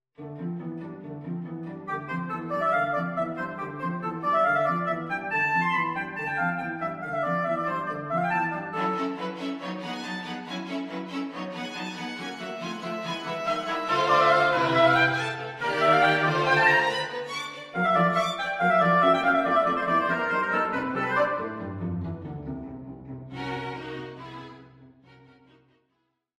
from III Vivace:
A charming English neoclassical work in three movements: